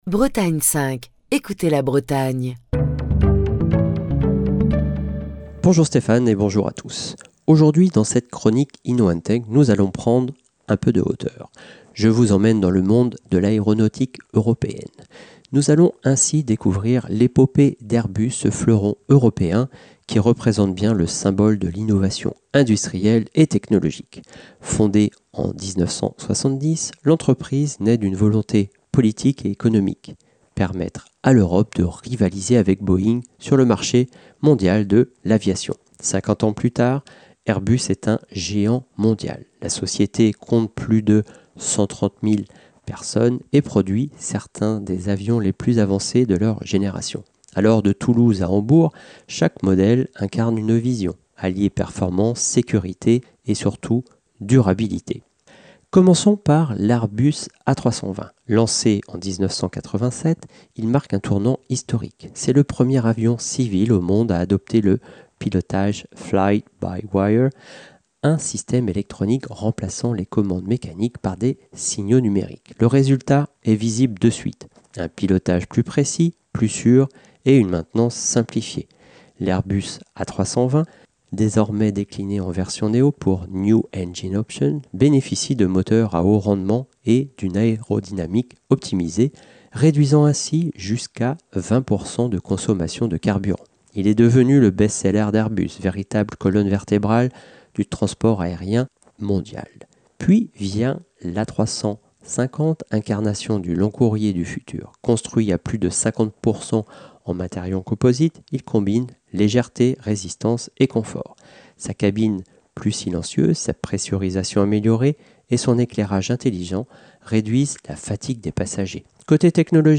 Chronique du 27 novembre 2025.